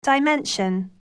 영국[daiménʃən]